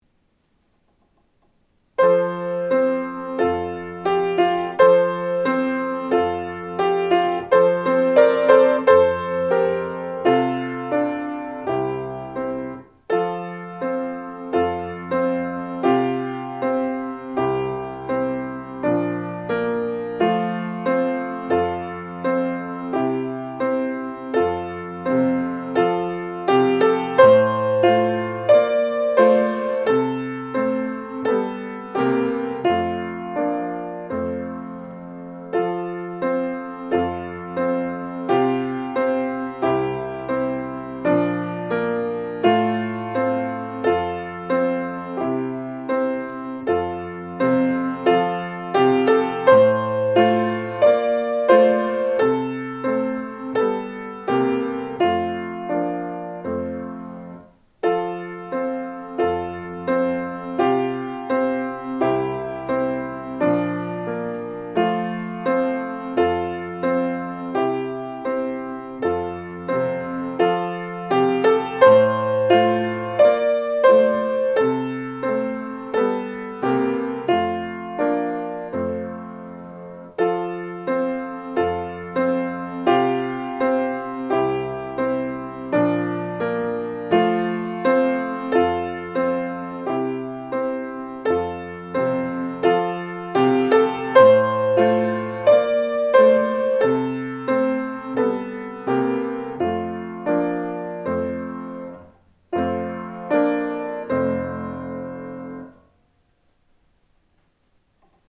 ⭐予選審査では、下記の課題曲の伴奏をご利用いただくことも可能です。
ゆりかごの唄前奏４小節